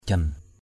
/ʥʌn/ (đg.) dập, đập = frapper. jan ralaow ka rataoh jN r_l<| k% r_t<H dập thịt cho mềm = frapper la chair pour l’attendrir. bangi lo jan gaok jan glah...